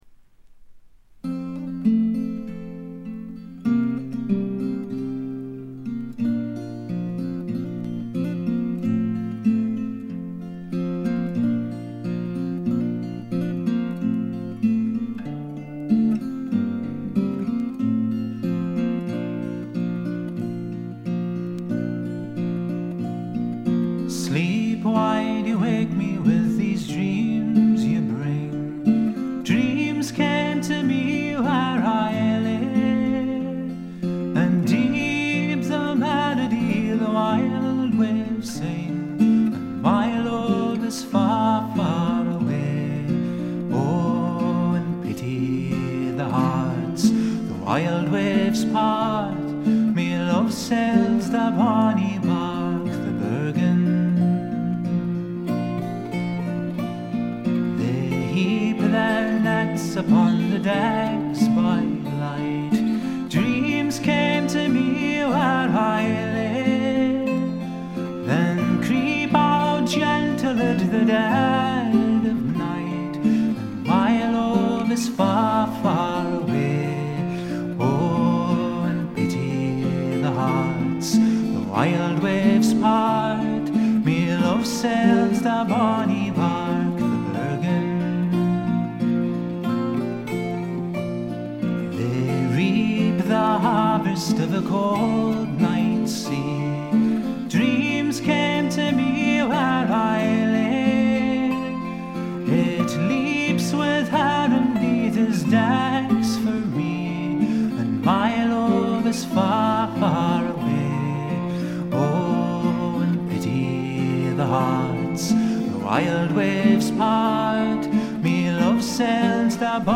ホーム > レコード：英国 フォーク / トラッド
試聴曲は現品からの取り込み音源です。
Electric Guitar, Drums [Linn], Backing Vocals